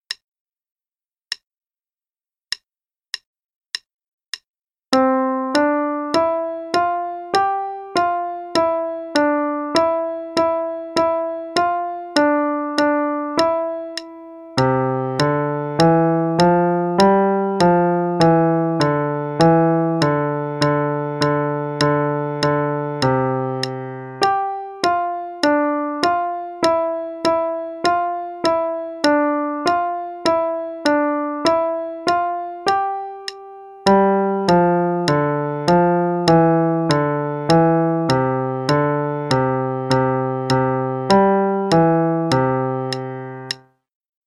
qn=100